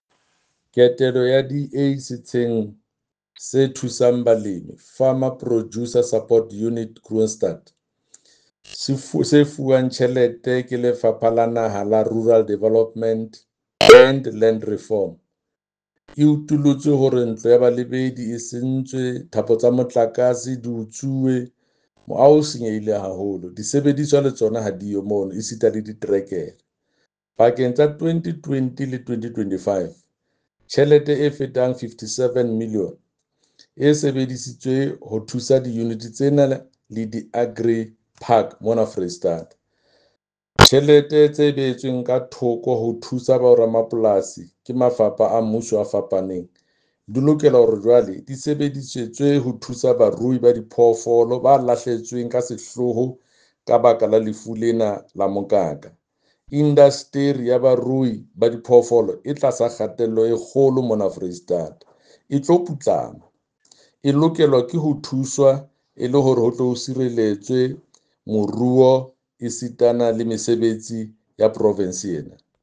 Sesotho soundbite by Jafta Mokoena MPL as well as images here, here, here, here, and here